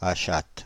Prononciation
Synonymes ami Prononciation France (Île-de-France): IPA: /a.ʃat/ Le mot recherché trouvé avec ces langues de source: français Les traductions n’ont pas été trouvées pour la langue de destination choisie.